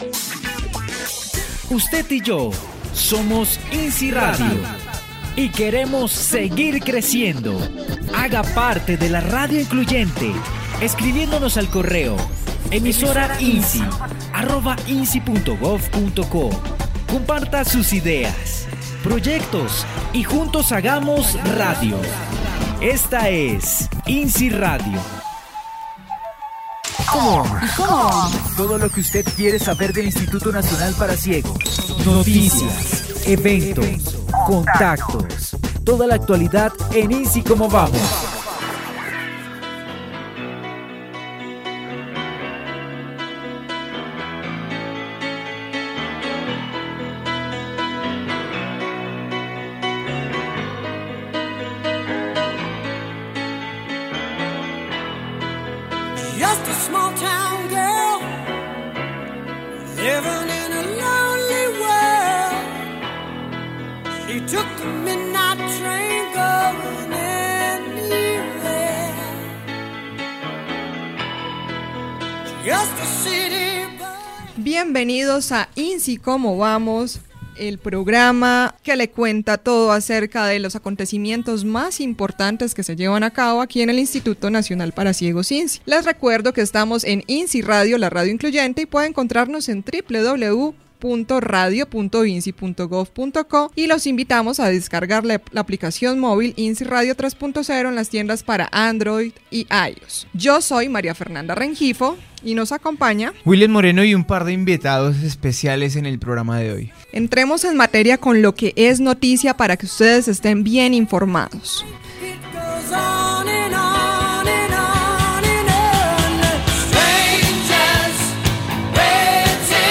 Noticiero informativo institucional